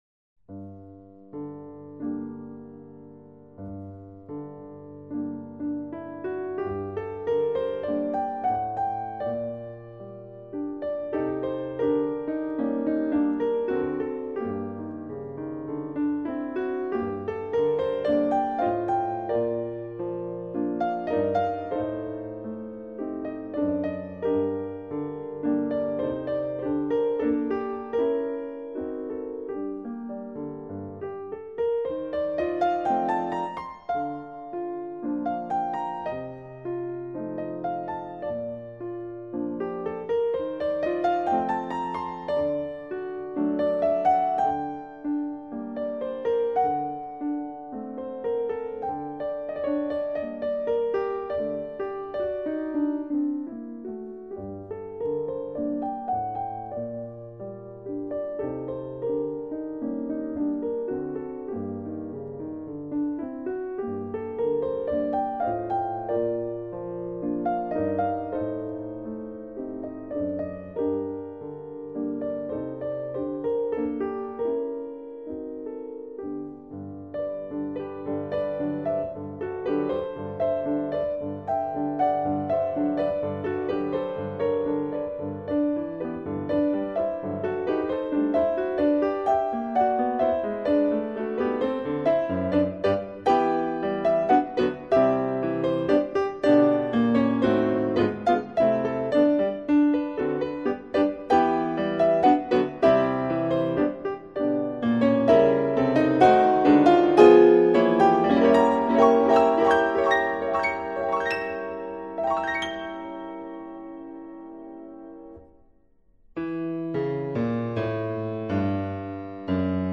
piano10.mp3